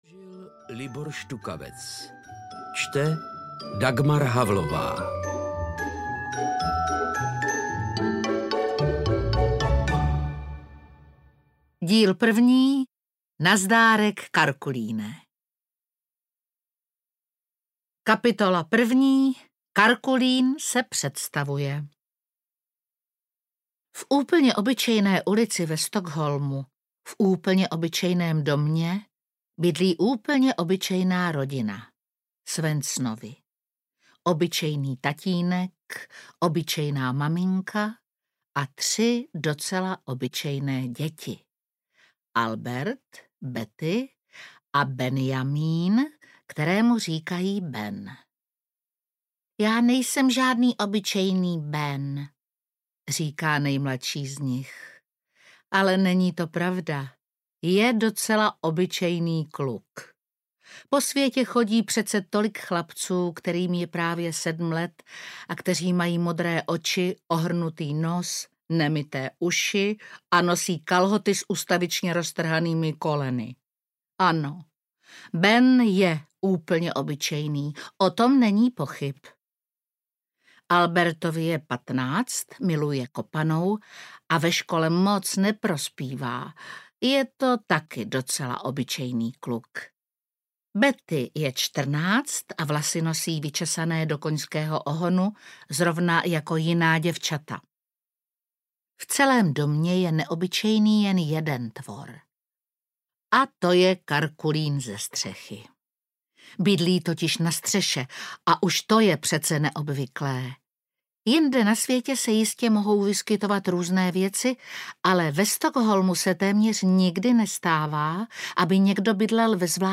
Audio knihaKarkulín ze střechy
Ukázka z knihy
• InterpretDagmar Havlová